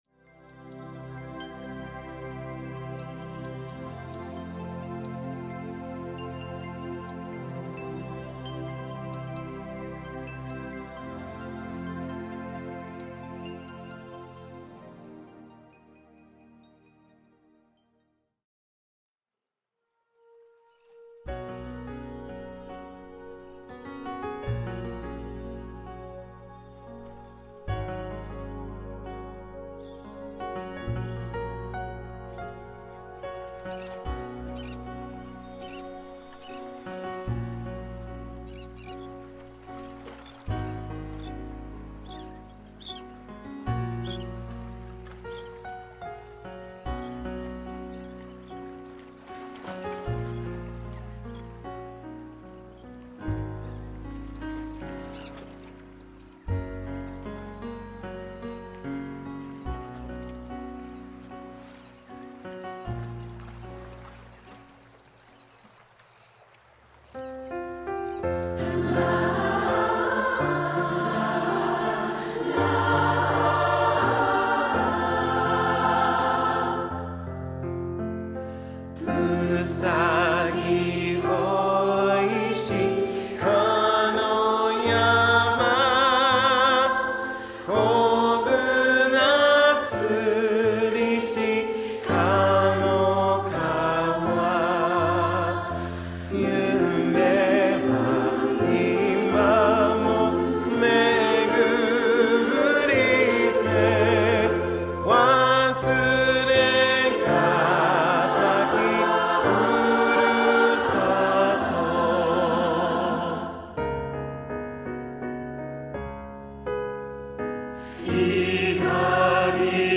曲名：ふるさと・故郷～FURUSATO・HOME 歌：JL GOSPEL FAMILY VIRTUAL CHOIR ※この動画をYouTubeでご覧になる場合は https